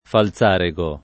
Falzarego [ fal Z# re g o ]